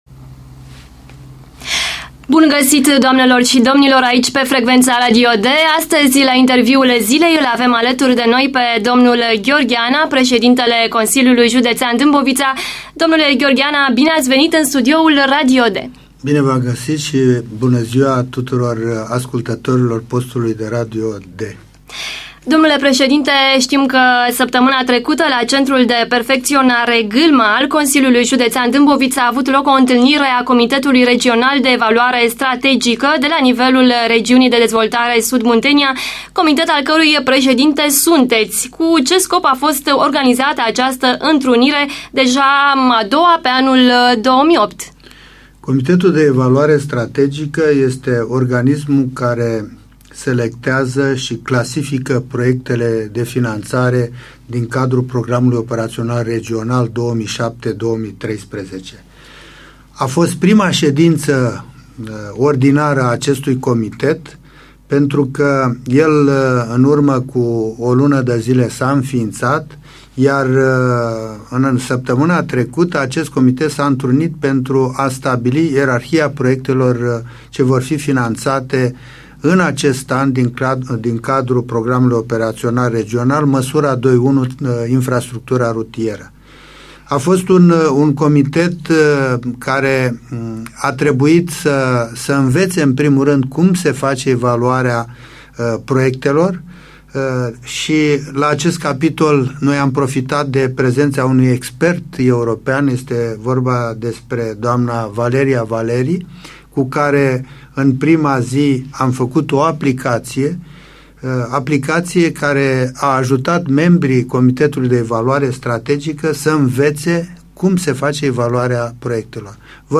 Gheorghe Ana - Interviu Radio D:3 martie 2008
Gheorghe Ana - Interviu Radio D:3 martie 2008 Dată: 06.03.2008 Interviul Domnului Gheorghe Ana, Pre ş edintele Consiliului Judeţean Dâmboviţa, acordat postului Radio D în ziua de 3 martie 2008: format audio ~ 43 MB Înapoi